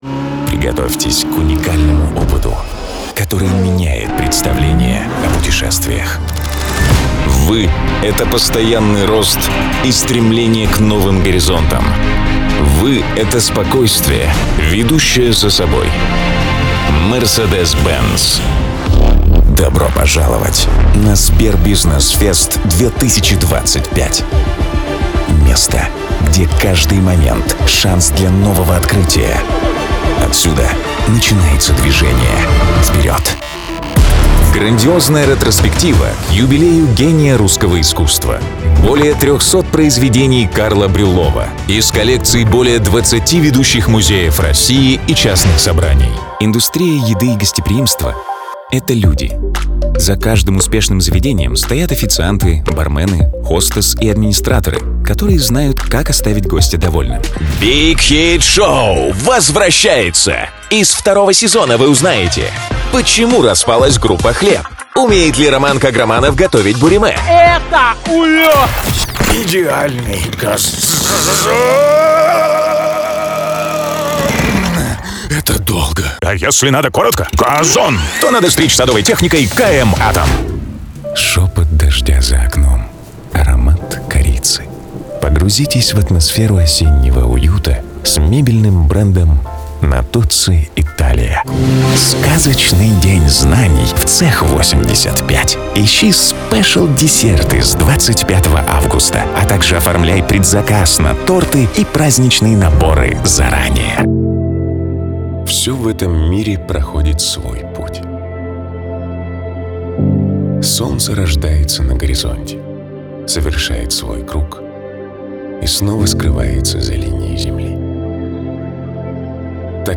Муж, Рекламный ролик/Средний
Rode NT1a + Scarlett 2i4